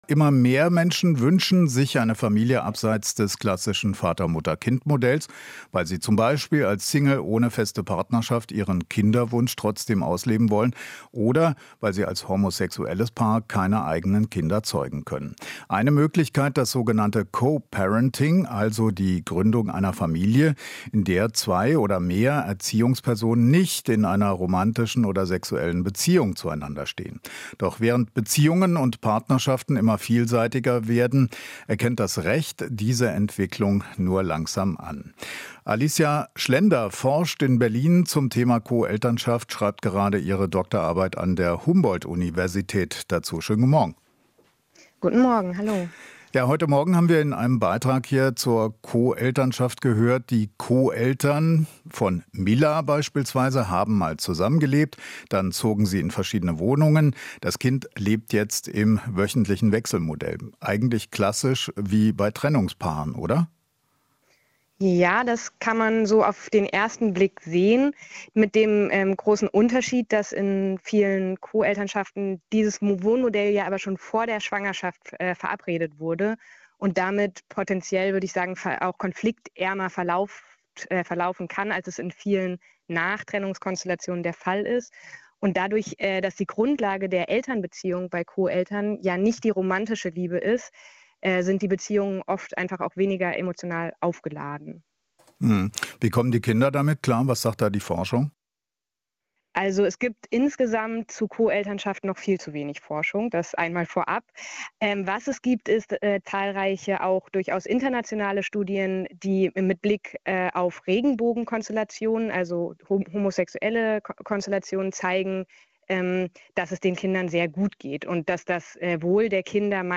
Interview - Gender-Forscherin zum Co-Parenting: "Kinderwohl steht im Mittelpunkt"